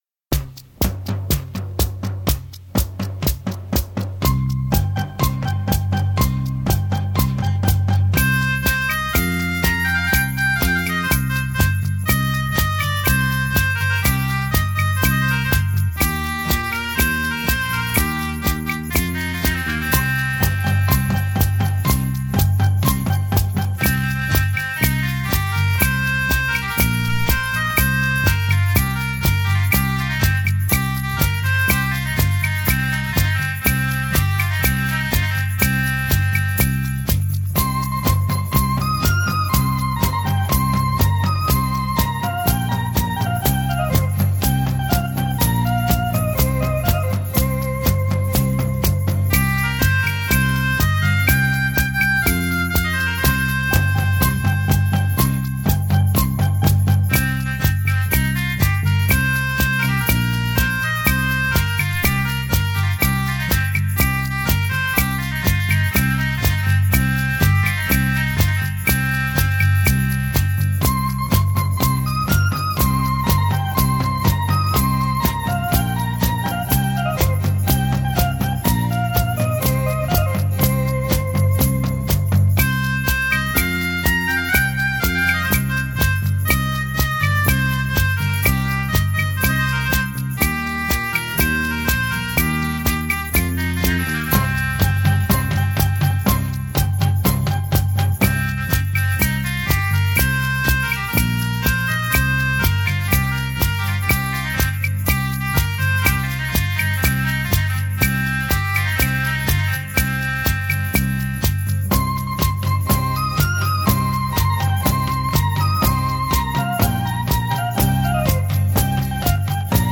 台湾民谣专集